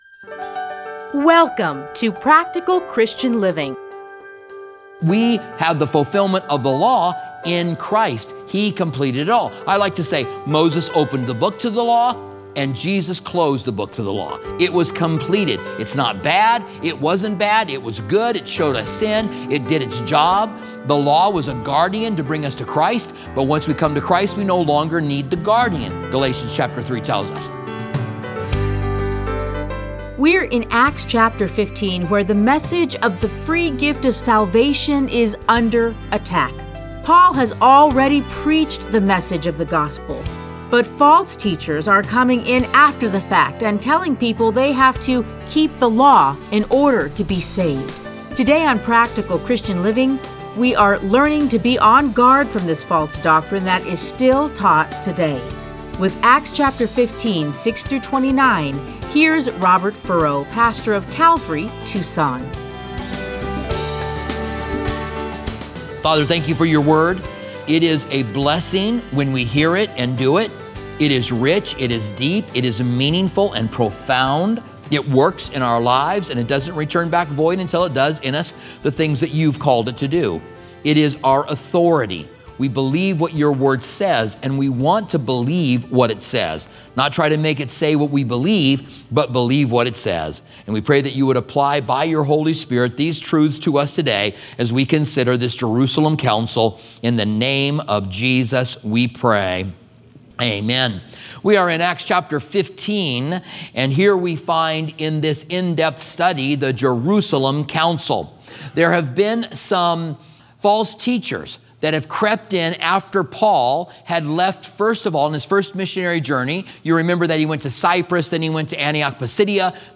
teachings are edited into 30-minute radio programs titled Practical Christian Living. Listen to a teaching from Acts 15:6-29.